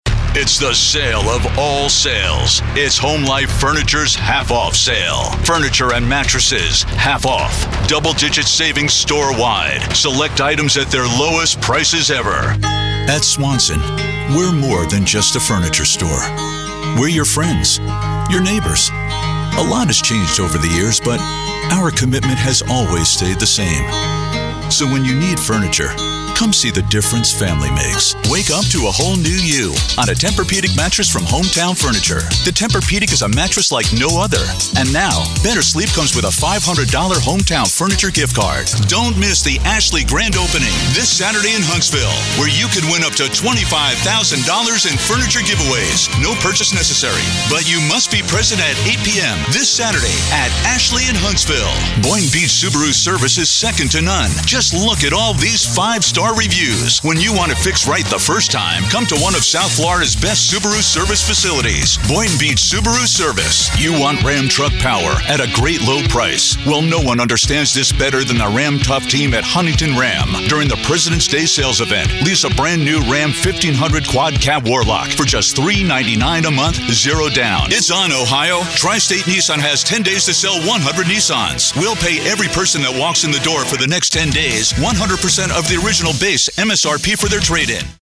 English - USA and Canada
Middle Aged
Retail Furniture Demo.mp3